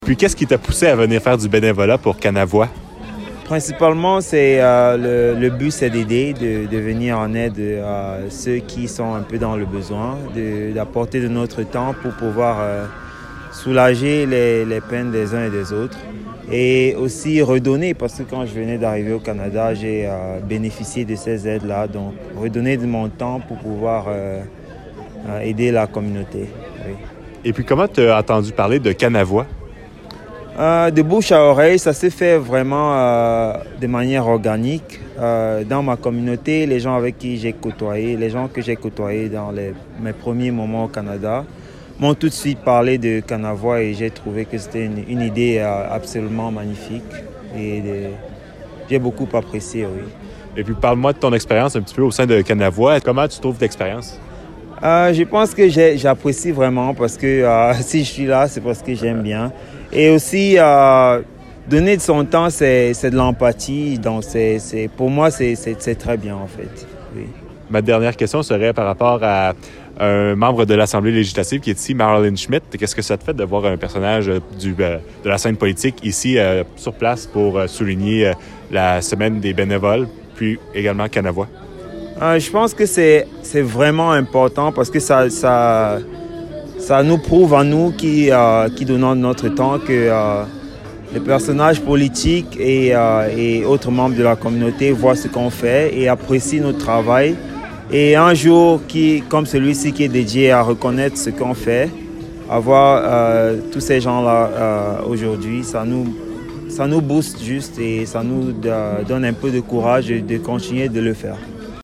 La cérémonie a eu lieu à l'extérieur de La Cité francophone, tout près du Café Bicyclette.
Entrevue-Ceremonie-CANAVUA.mp3